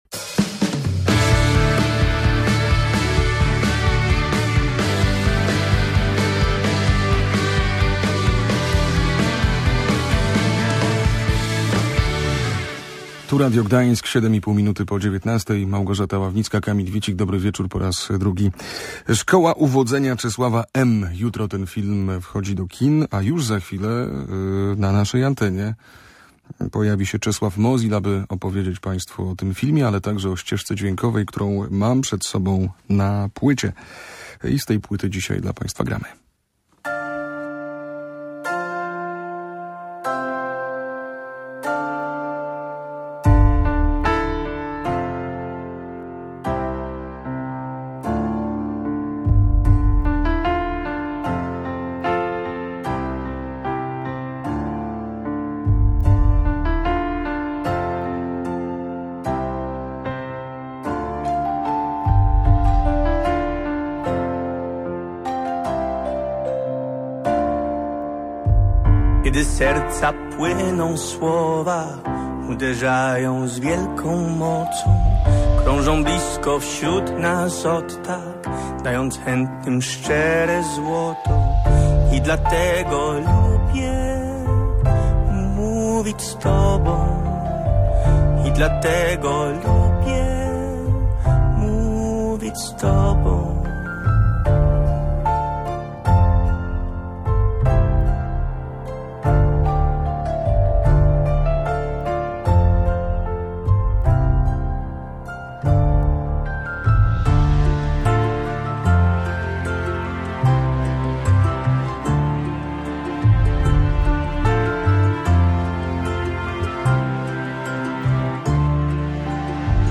Mam duński akcent i jeżeli kogoś mógłbym zagrać, to tylko samego siebie – mówił muzyk.
czeslaw_rozmowa.mp3